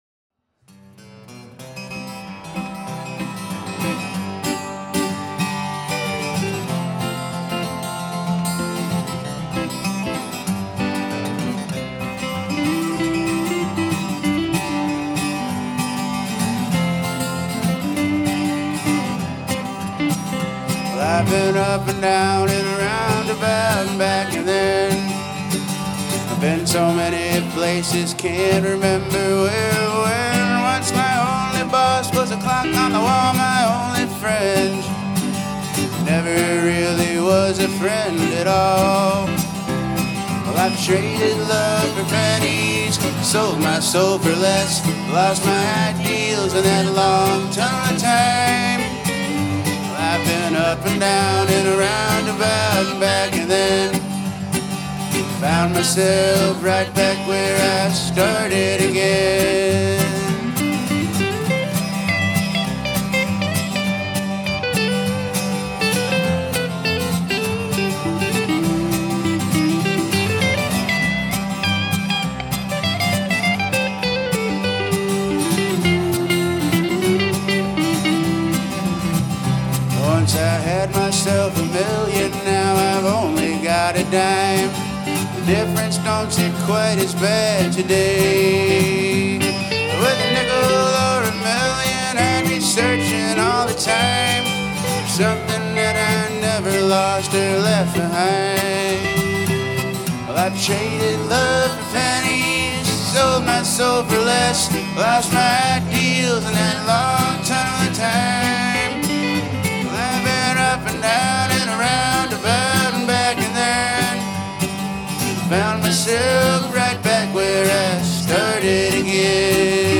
Guitar/Vocals
Mandolin/Electric Guitar/Vocals
Bass/Vocals